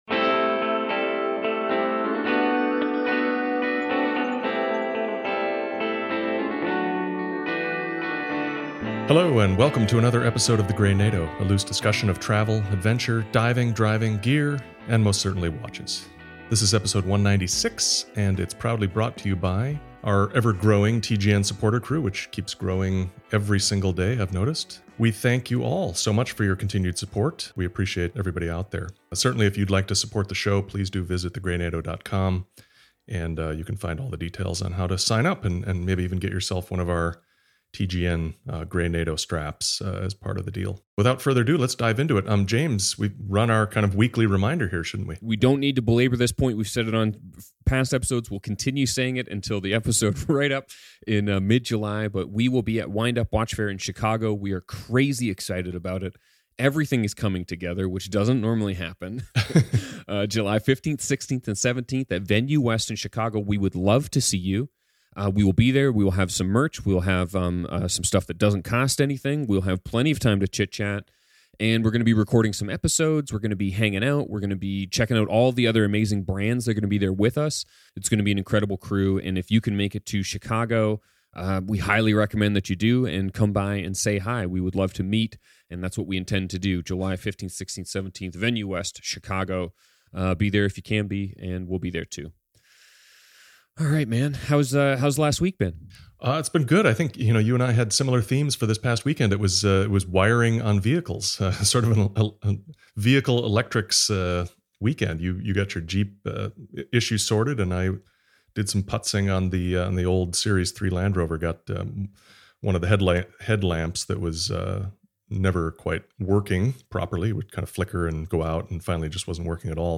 This episode of The Grey Nato podcast is a discussion about scuba diving in honor of World Oceans Day.